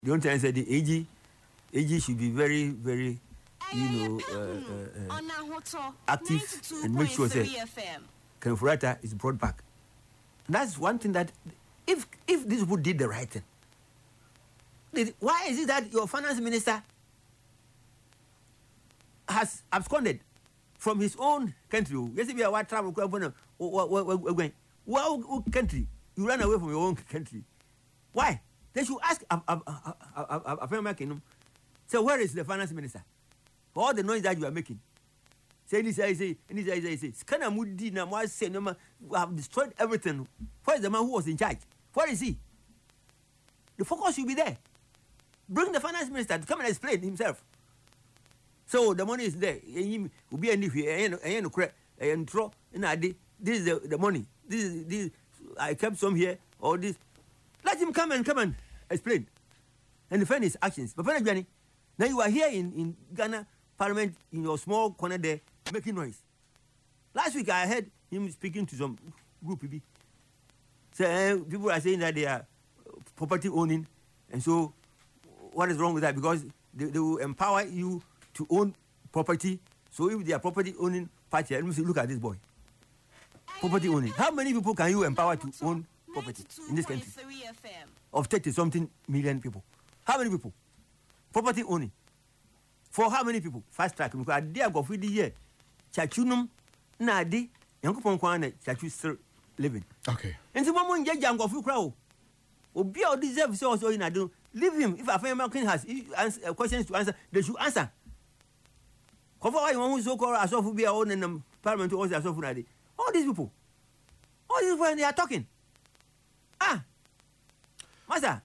Speaking on Ahotor FM’s Yepe Ahunu programme on Saturday, February 21, he questioned why the former minister had reportedly left the country if there was no wrongdoing during his tenure.